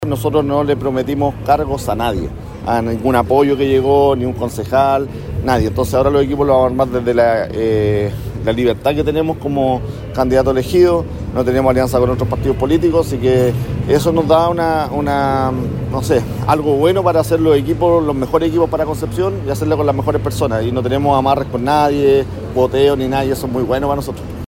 Entre aplausos y felicitaciones llegó a un céntrico café penquista el alcalde electo por la comuna de Concepción, Héctor Muñoz, para dialogar con la prensa en las horas posteriores a su triunfo electoral.